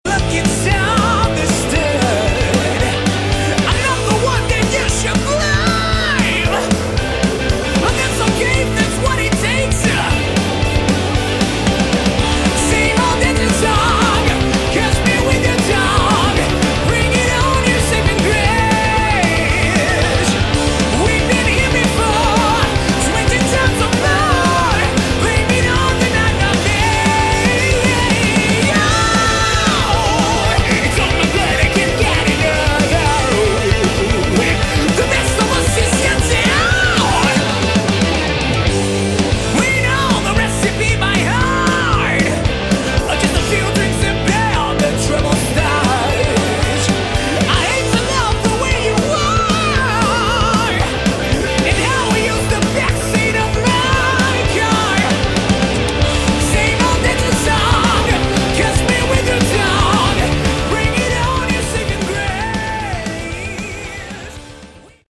Category: Hard Rock
vocals
guitars
bass
drums
keyboards, backing vocals
Pure Melodic HR with huge choruses and strong production .